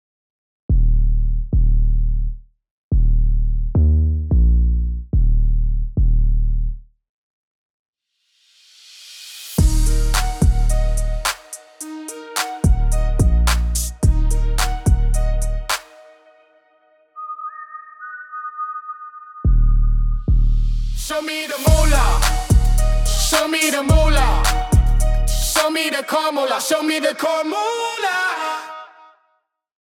Think upbeat, windows-down kinda energy.